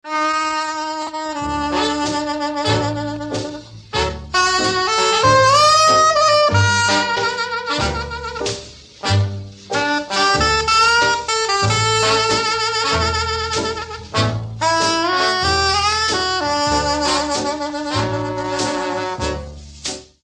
Musique